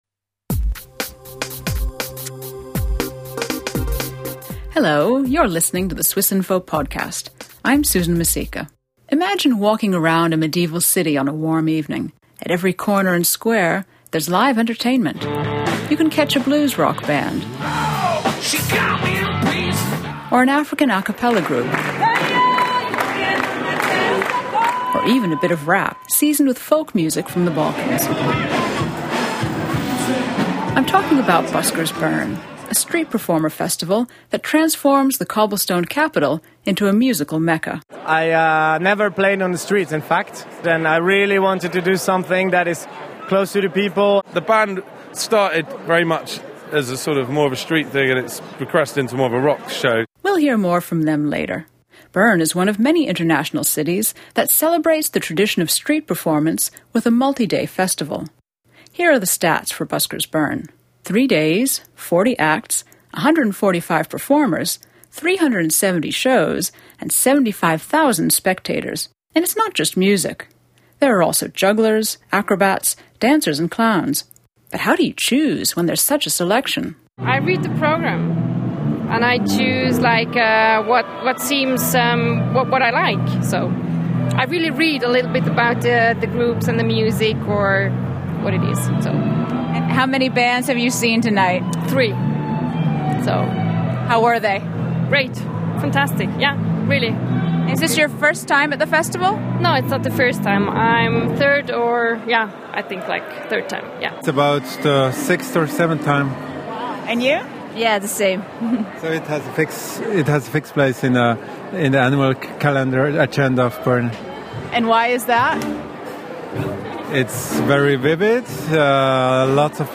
Bern is one of many international cities that celebrates the tradition of street performance with a multi-day festival. Join us for the Buskers Bern festival, and meet a musician who busks around Bern year-round.